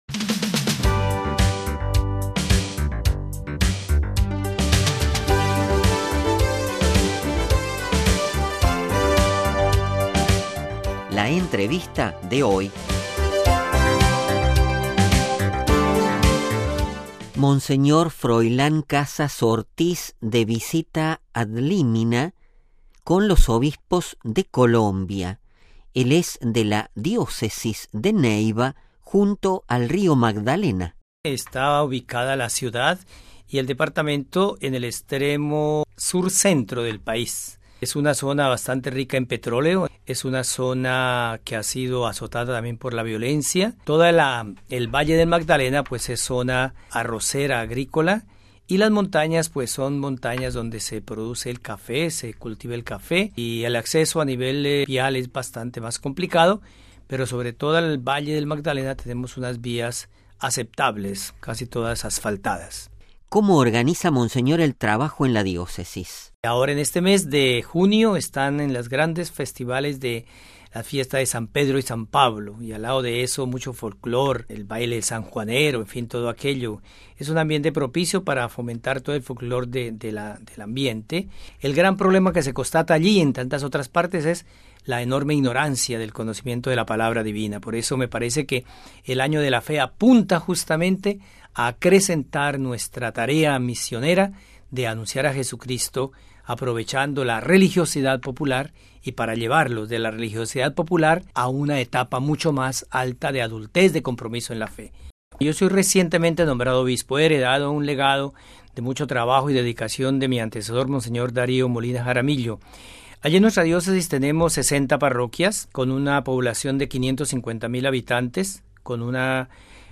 La entrevista de hoy